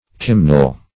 Search Result for " kimnel" : The Collaborative International Dictionary of English v.0.48: Kimnel \Kim"nel\, n. A tub.